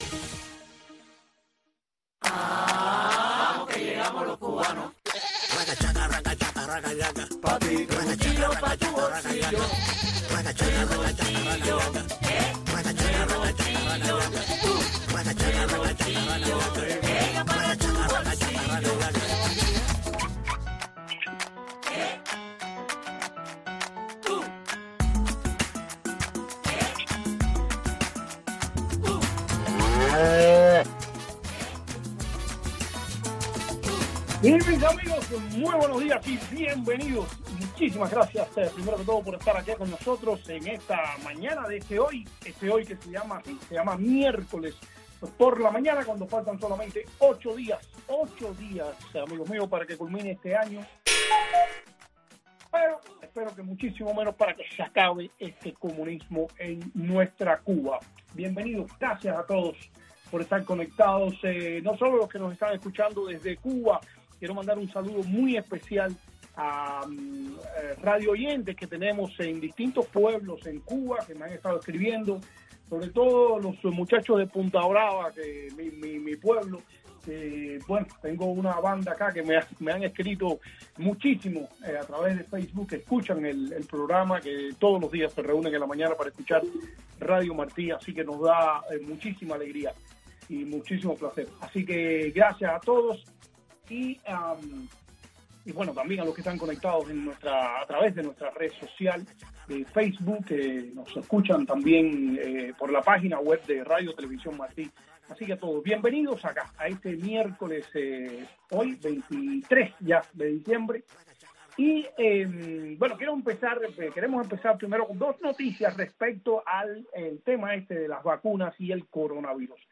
comparte entrevistas, anécdotas y simpáticas ocurrencias